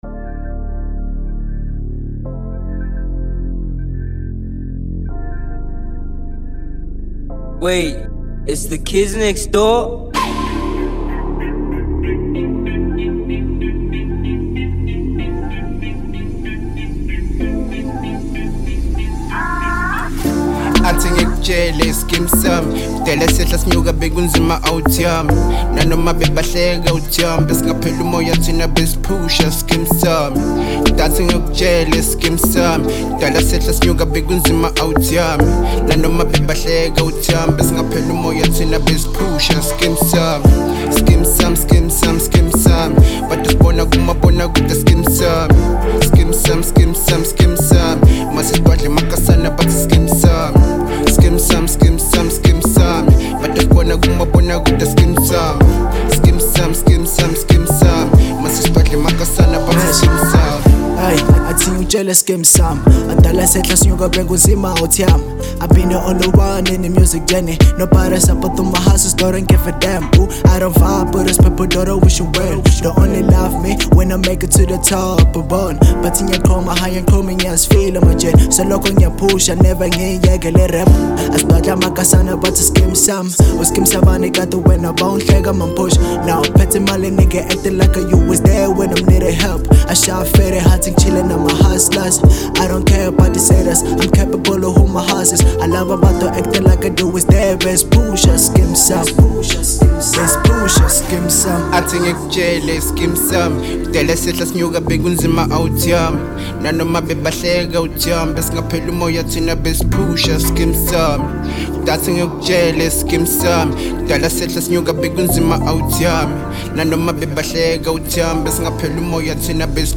03:48 Genre : Hip Hop Size